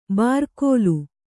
♪ bārkōlu